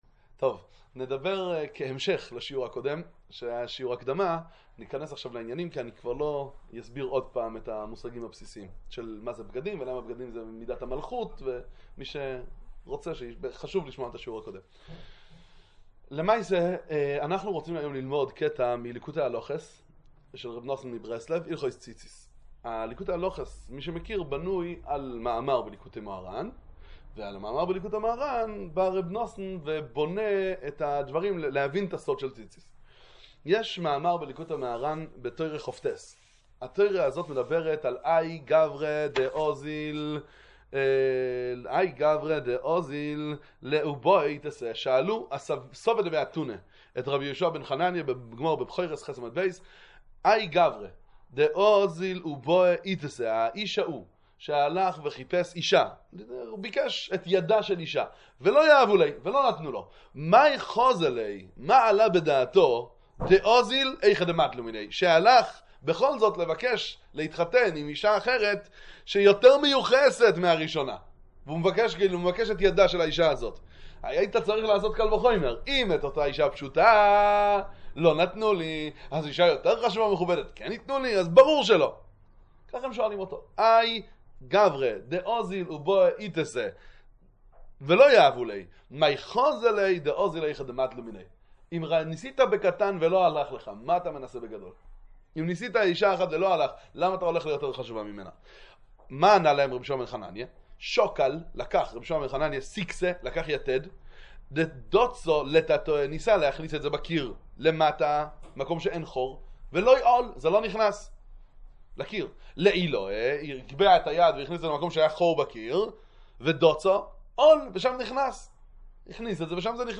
שיעור 1 מתוך 2 על ליקוטי הלכות הלכות ציצית הלכה א'.